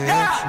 Vox
Ya 4.wav